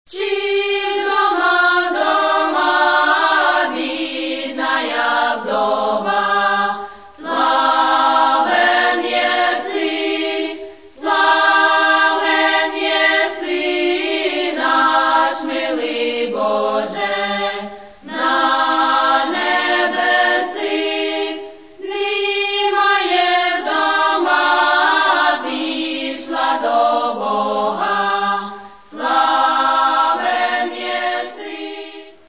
Каталог -> Народная -> Акапельное пение и хоры